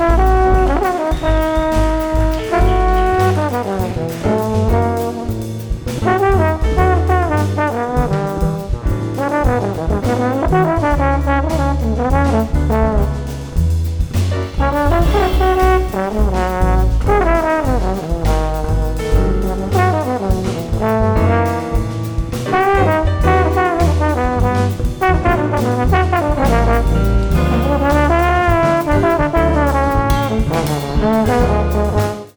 Choose a jazz note below.